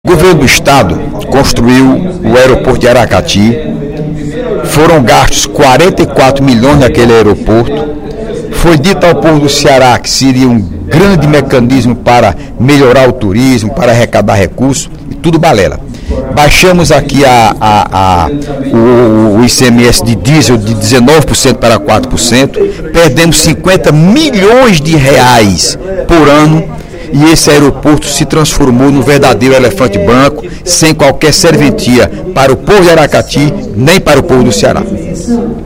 O deputado Heitor Férrer (PSB) criticou, durante o primeiro expediente da sessão plenária desta sexta-feira (01/04), a construção do Aeroporto Dragão do Mar, localizado em Aracati. Segundo o parlamentar, o equipamento não funciona e é “um elefante branco” para o Estado.